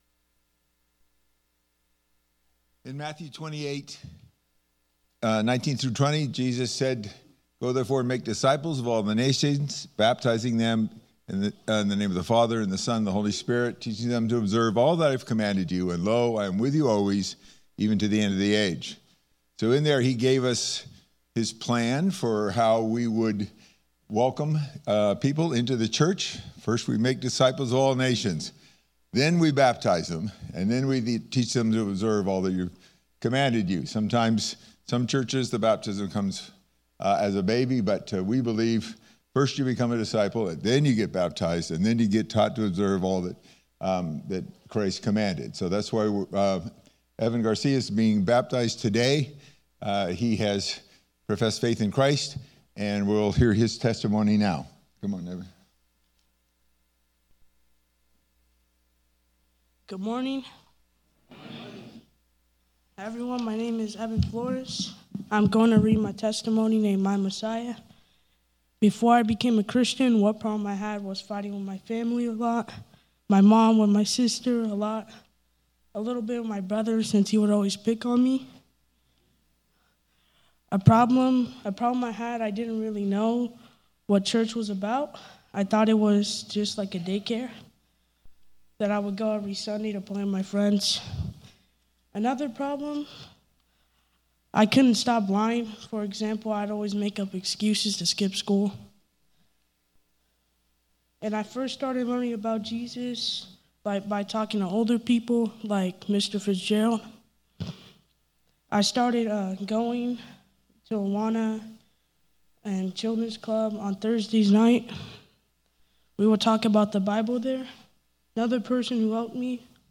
Series: Baptisms Topic: Testimony